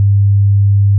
題材：2つのサイン波を分類する音声認識モデル
100Hzダウンロード
100Hz.wav